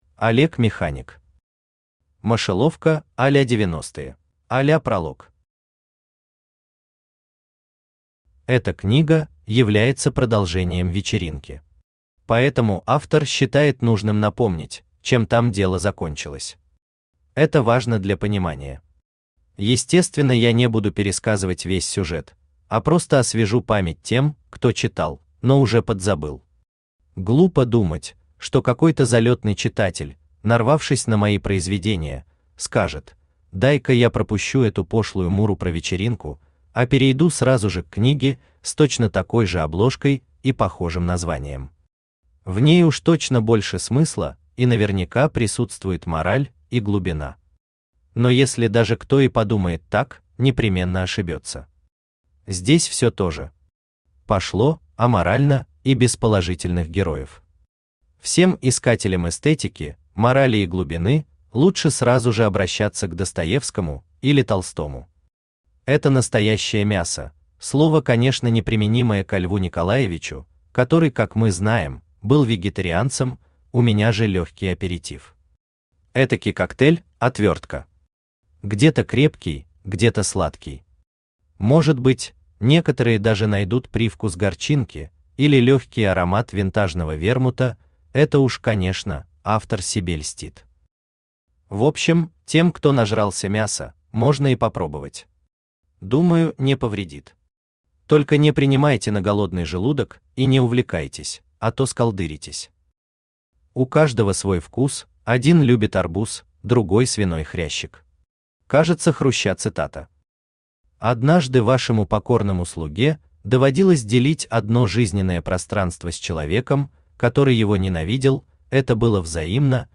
Аудиокнига Мышеловка а-ля 90-е | Библиотека аудиокниг
Aудиокнига Мышеловка а-ля 90-е Автор Олег Механик Читает аудиокнигу Авточтец ЛитРес.